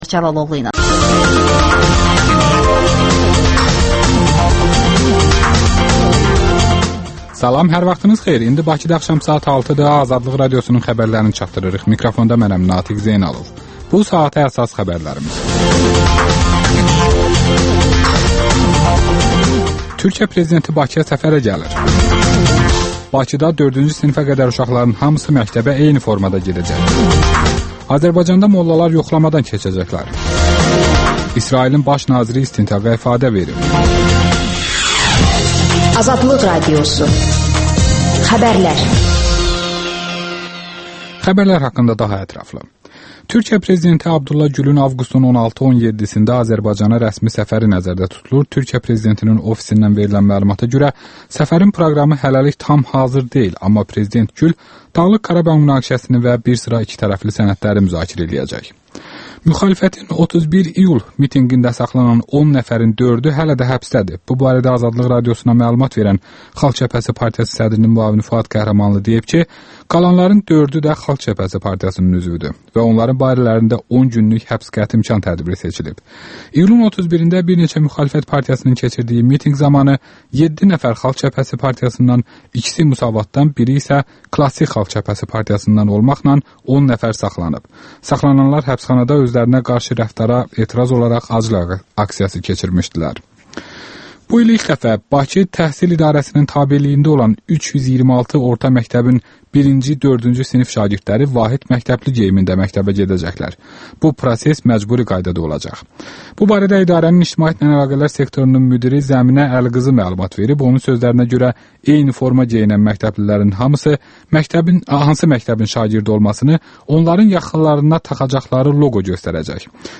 Azərbaycan Demokrat Partiyasının sədri Sərdar Cəlaloğlu dinləyicilərin suallarına cavab verir